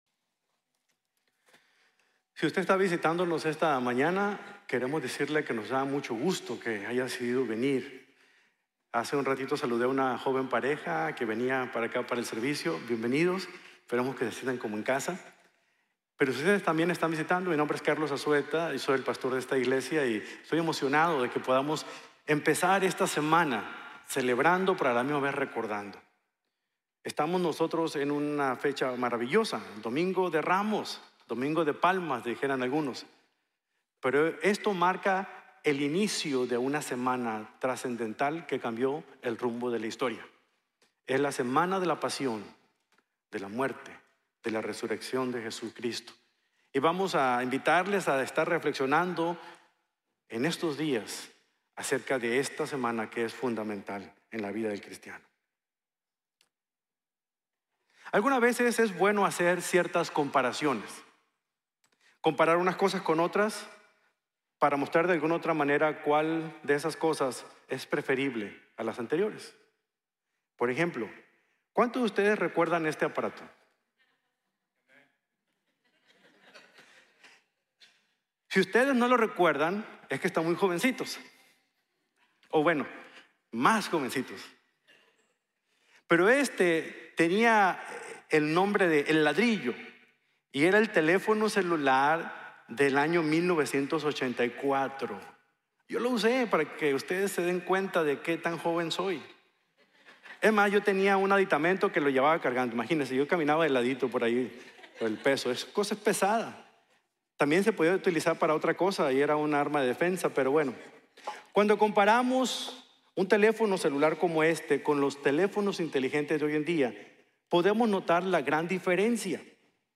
Cabalgando con Jesús | Sermon | Grace Bible Church